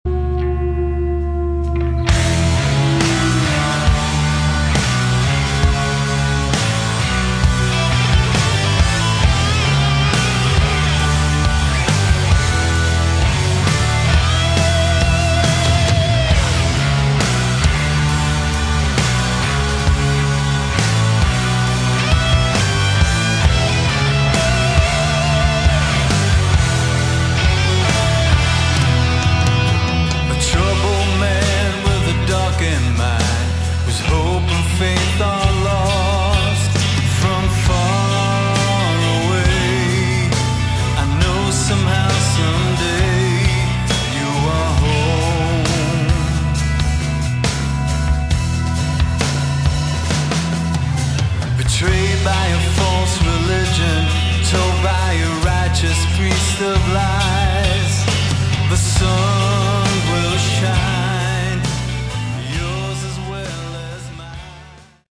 Category: Melodic Hard Rock
lead & backing vocals
lead & rhythm guitar, backing vocals
drums, percussion, backing vocals
organ, keyboards, backing vocals